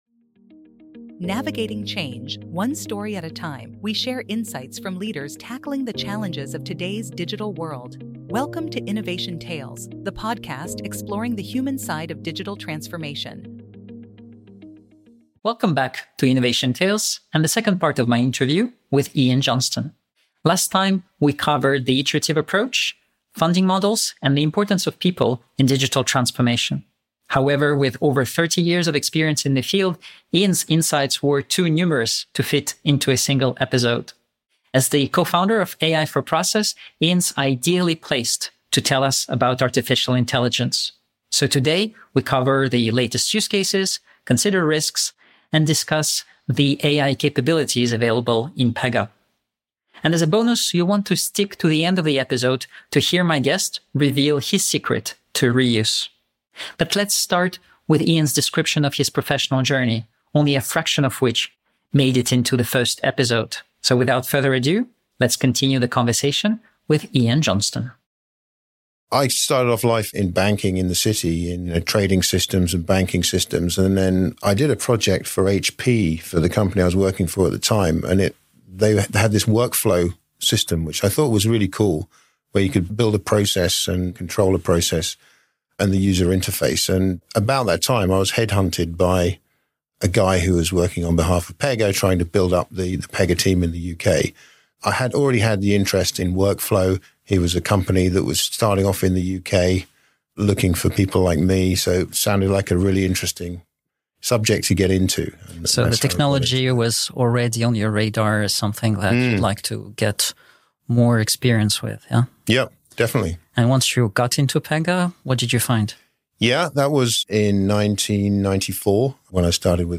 Pega AI, An Extended Conversation